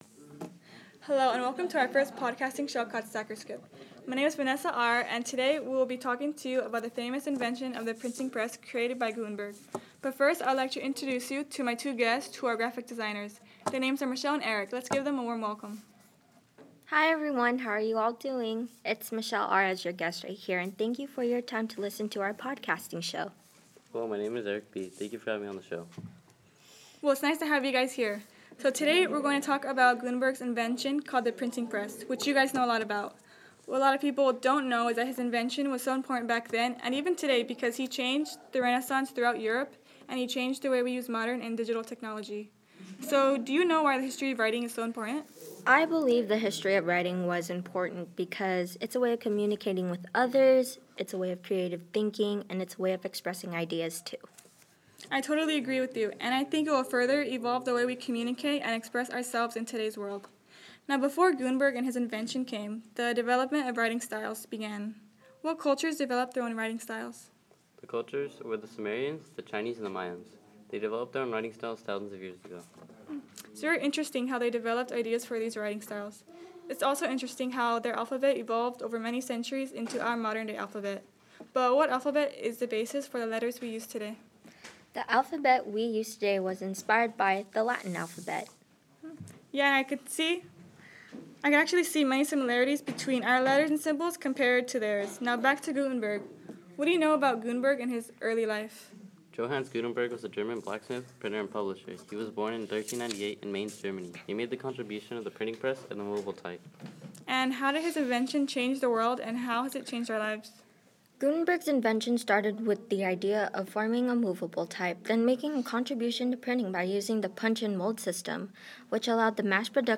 Printing press